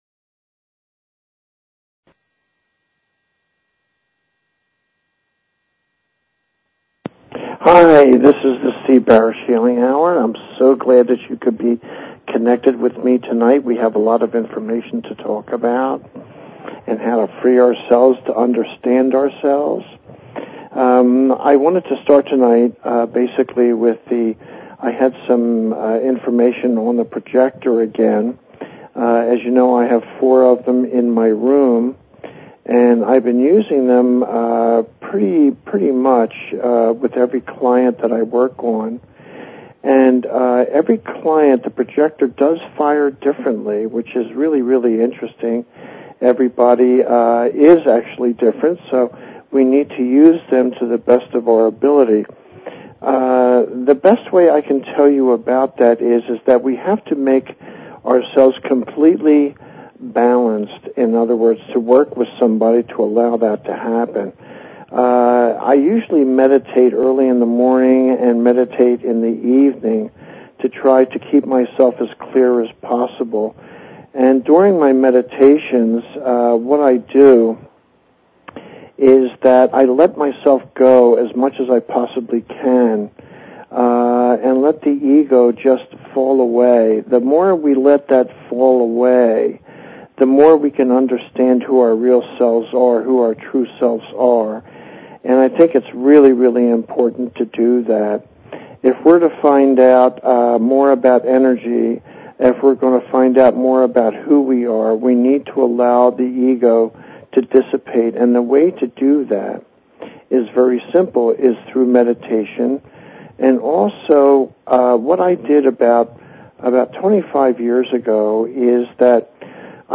Talk Show Episode, Audio Podcast, The_Healing_Hour and Courtesy of BBS Radio on , show guests , about , categorized as